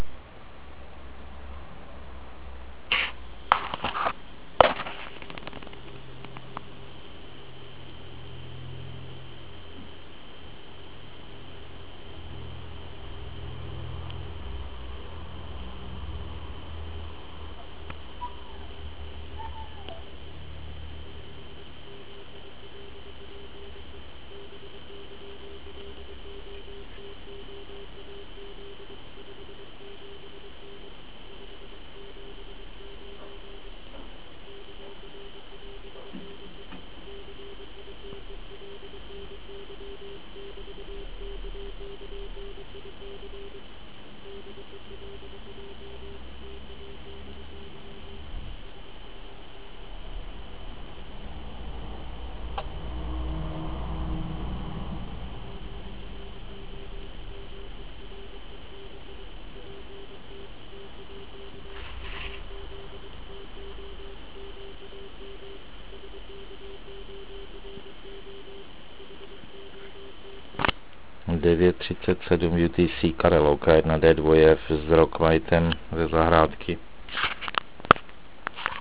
Pádlovka a špatné podmínky.
Od naprosto nečitelného signálu do naprosto čitelného. Ano to je těch bratru 10 deci (Bellu).